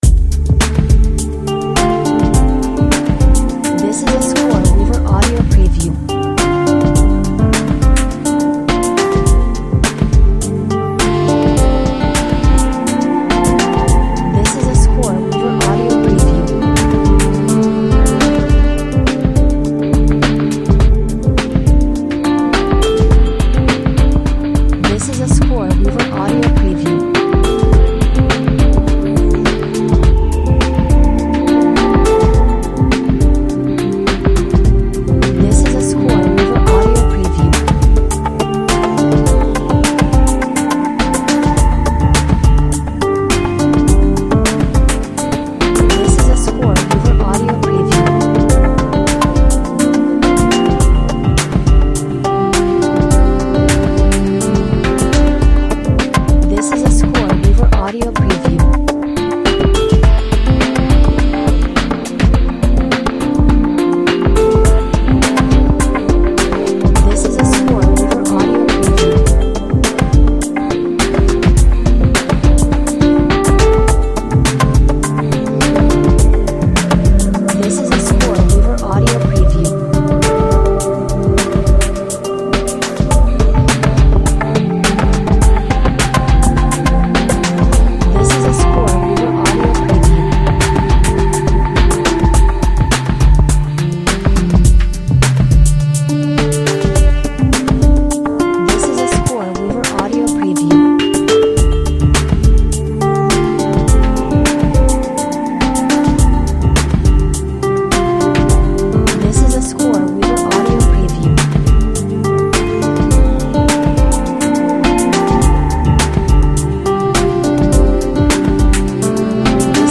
Smooth lounge electronica with a lovely guitar melody.